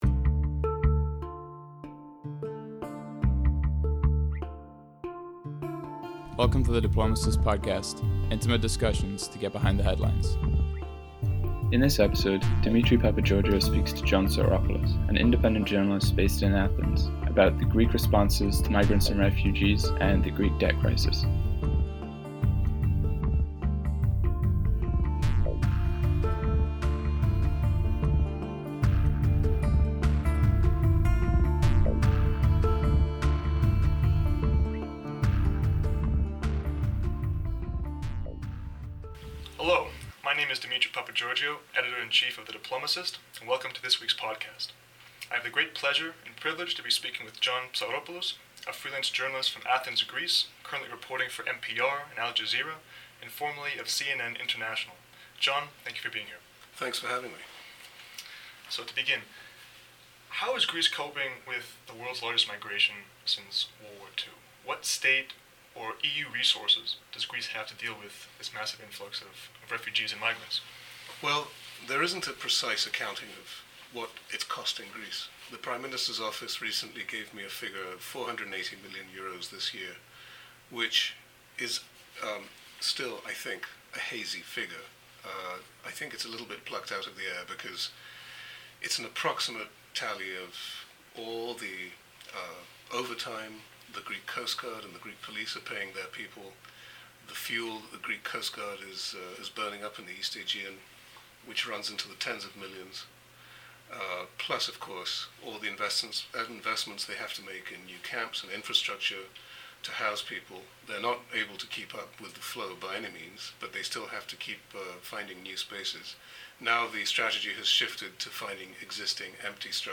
Expert